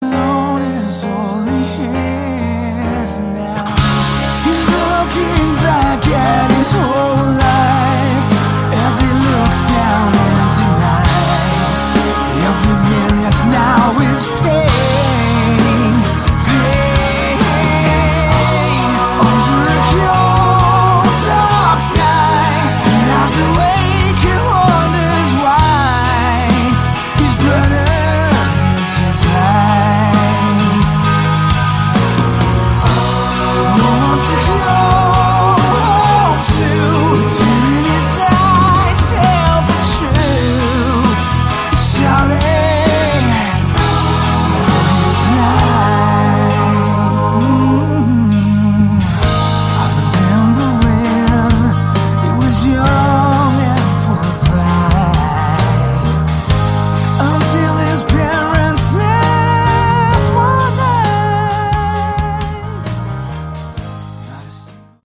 Category: Melodic Rock
lead guitars, vocals
drums
keyboards, vocals
bass, vocals